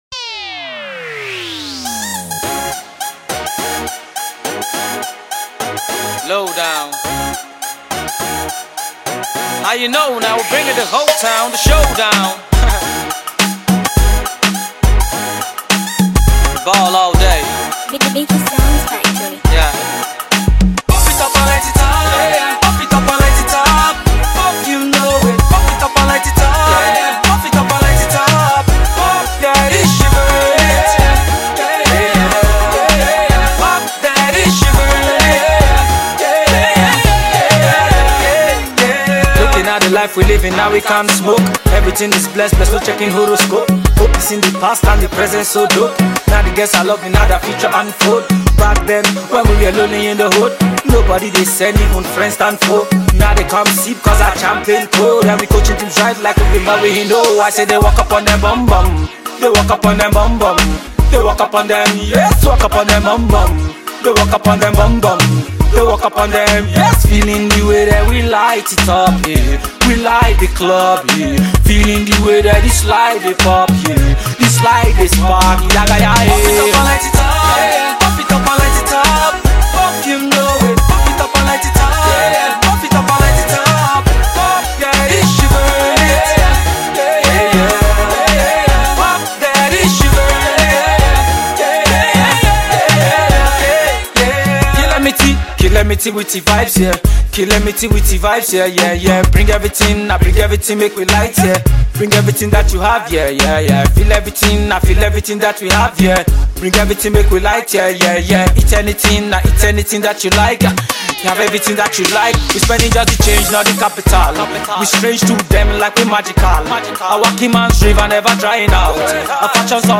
Rap
a bold vocal ability and enticing lyrics